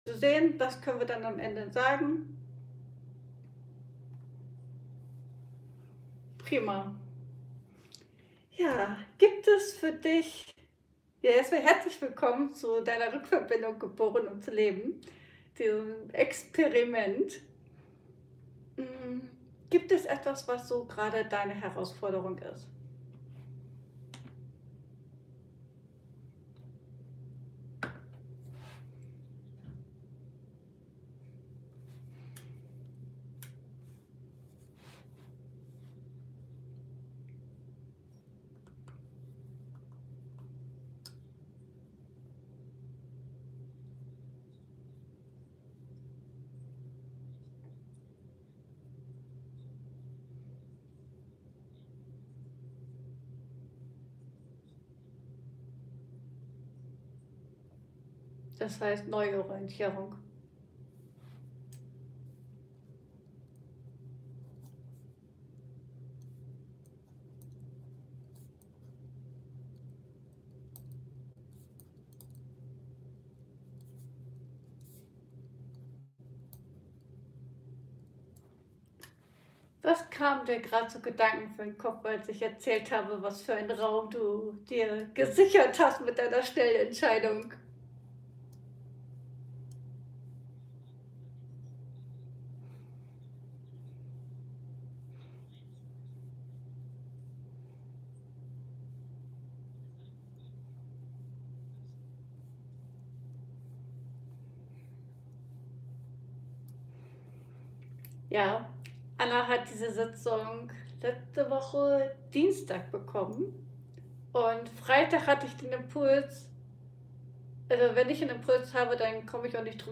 Seelenraum Liveübertragung ~ Ankommen lassen Podcast